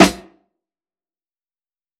Short Drum Room